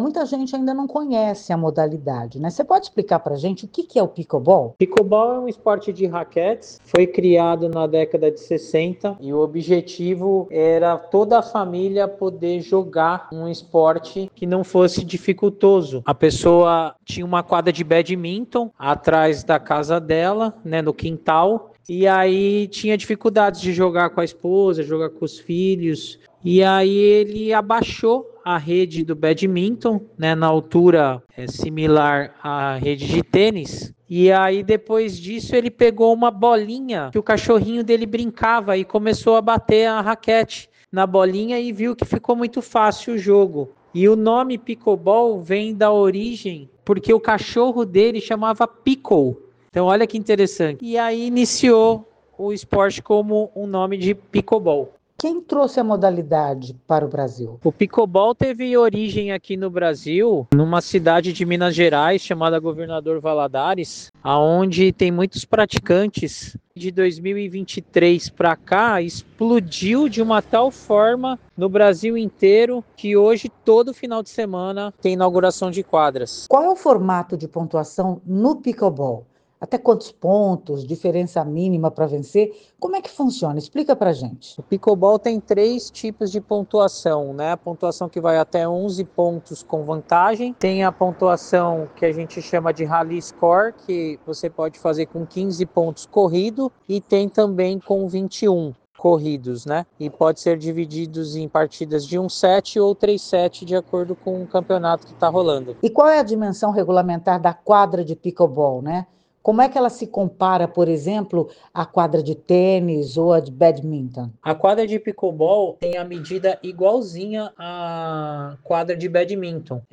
ENTREVISTA.wav